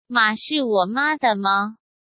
Mǎ shì wǒ mā de ma?